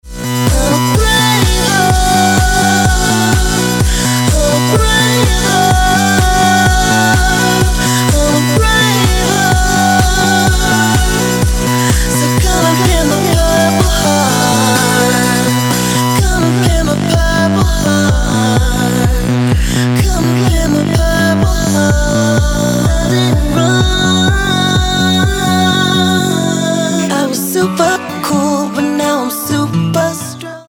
R&B speváčky